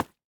Minecraft Version Minecraft Version latest Latest Release | Latest Snapshot latest / assets / minecraft / sounds / block / candle / break2.ogg Compare With Compare With Latest Release | Latest Snapshot